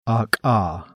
CONSONANTI (NON POLMONARI)